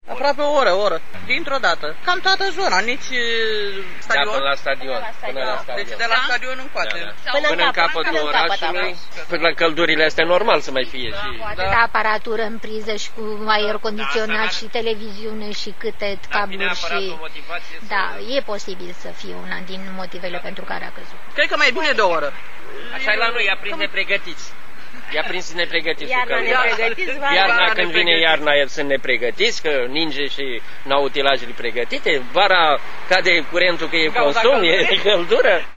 Fara iluminat stradal, traficul in oras a fost infernal. Aproape  fara exceptie, toti slobozenii au iesit din case, la aer, in asteptarea curentului electric: